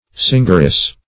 Singeress \Sing"er*ess\, n.